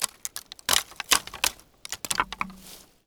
wood_tree_branch_move_20.wav